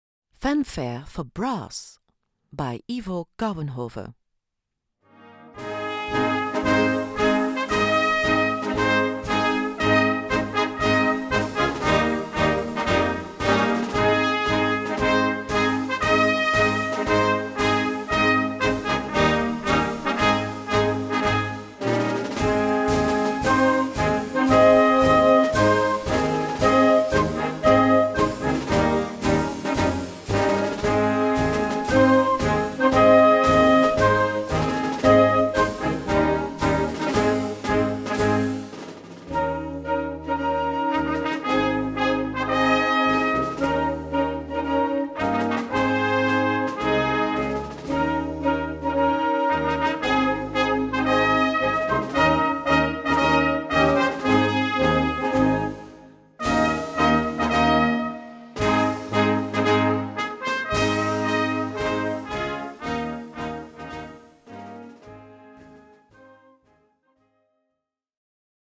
A4 Besetzung: Blasorchester PDF